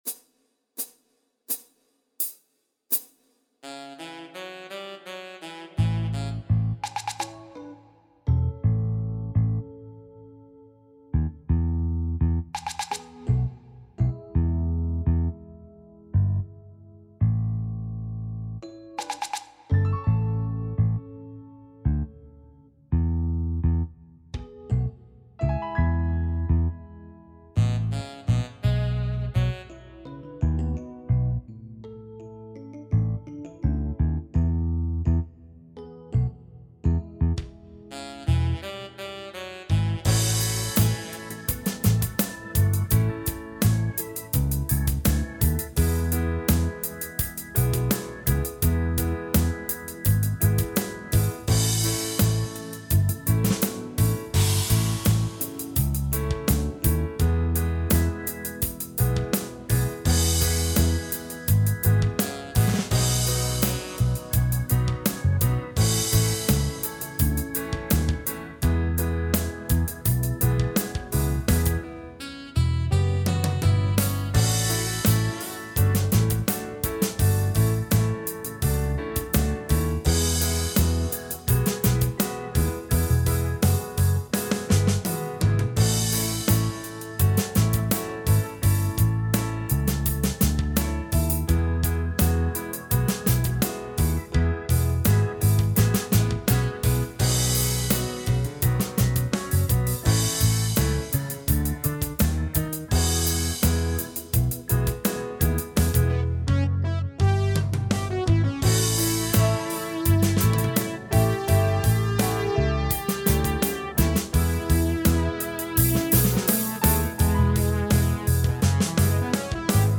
Lesson Sample